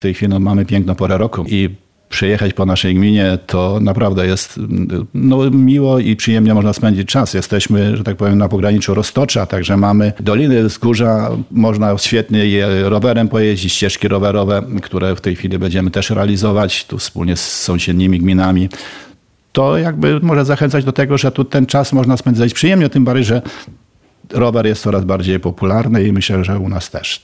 Wójt Godziszowa o turystycznych atrakcjach gminy
- Gmina Godziszów to świetne miejsce do życia oraz na turystyczne wypady - przekonuje wójt Józef Zbytniewski.